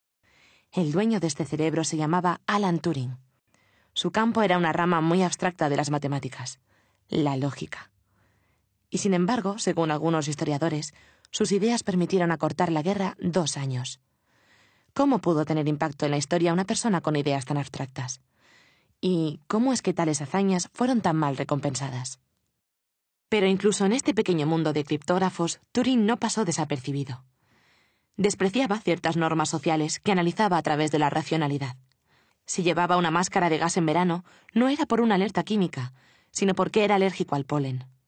Voz de un rango entre los 15 años y los 55.
Puedo poner voz clásica de locutora o salir de esos registros y anunciarte todo con un tono de calle, mas neutral.
Sprechprobe: Sonstiges (Muttersprache):